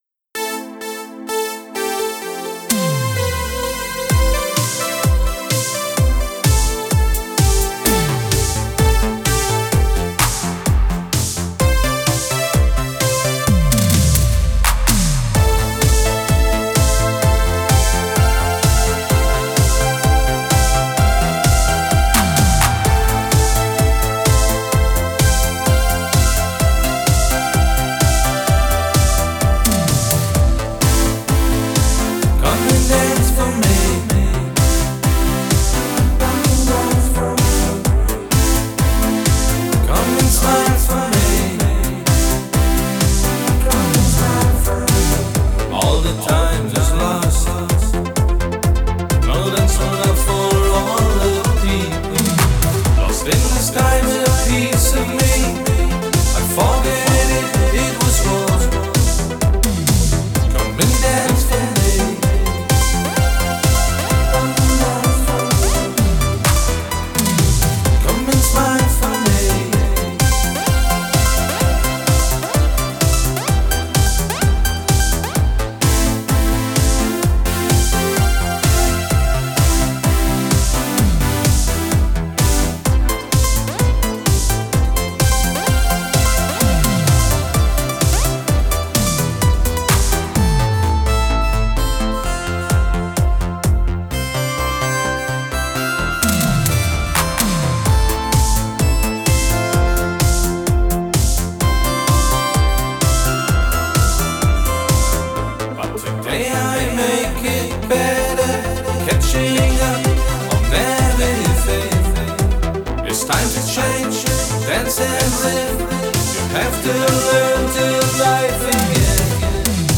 Synthpop · Synthwave · CD & Vinyl
Original Version · WAV & MP3